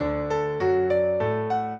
piano
minuet0-12.wav